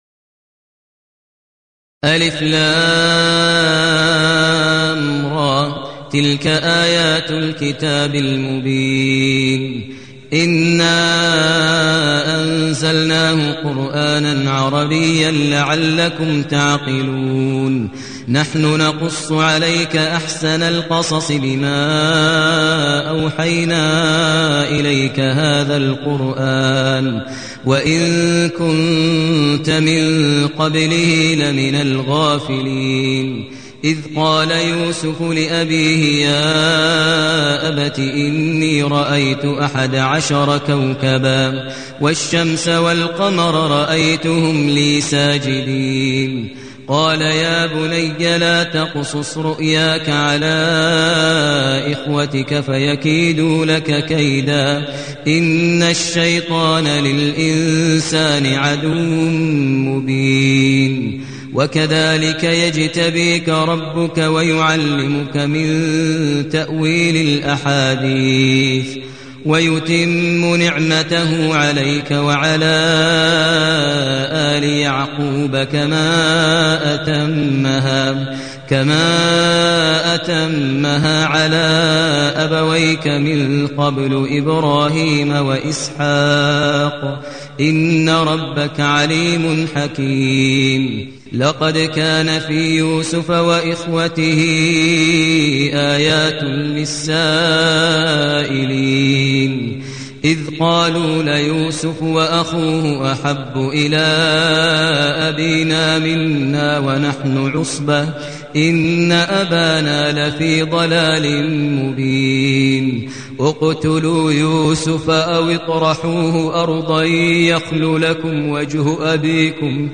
المكان: المسجد النبوي الشيخ: فضيلة الشيخ ماهر المعيقلي فضيلة الشيخ ماهر المعيقلي يوسف